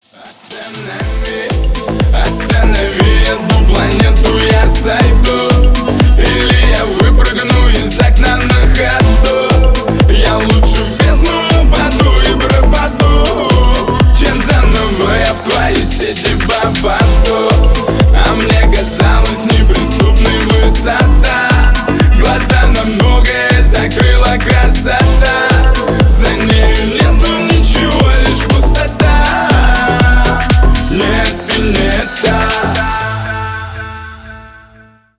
• Качество: 320, Stereo
поп
громкие
house